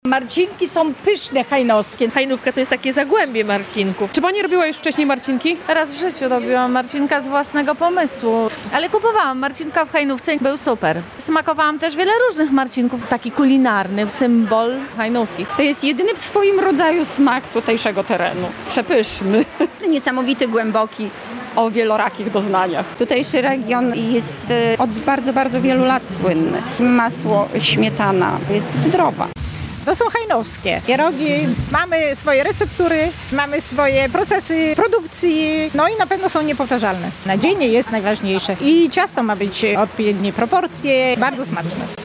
Można było obejrzeć tradycyjne rzemieślnicze wyroby, posłuchać muzyki ludowej i spróbować kuchni regionalnej. W Parku Miejskim w Hajnówce odbył się Jarmark Żubra.